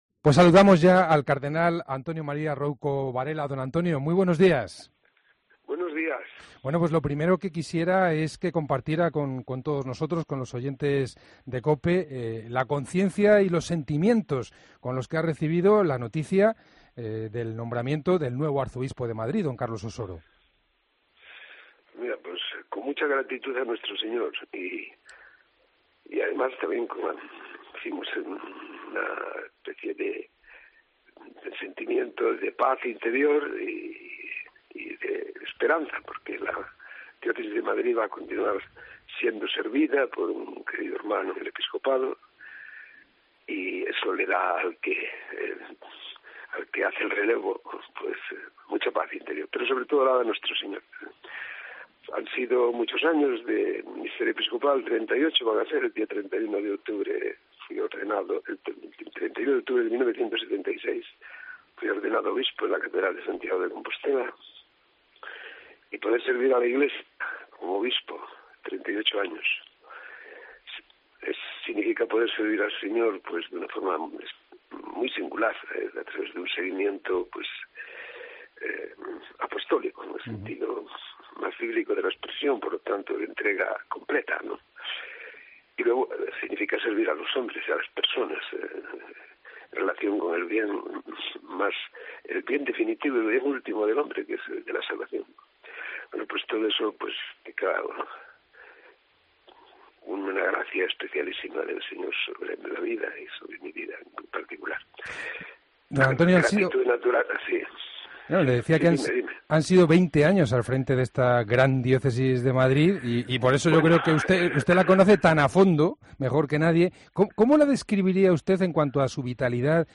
Entrevista al Cardenal Rouco Varela